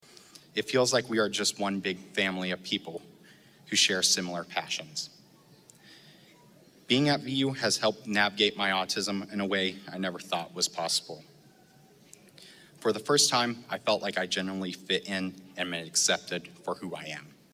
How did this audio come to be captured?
The VU class of 2024 attended Commencement on Saturday. Nearly 600 students walked at commencement this weekend in Vincennes.